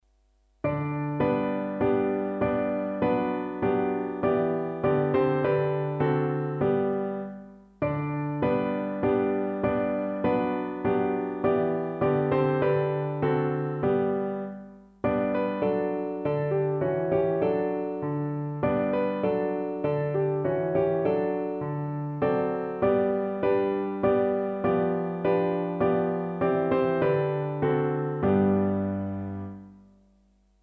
Grabiti voi, pastori dragi (O laufet, ihr Hirten) – SATB Partitura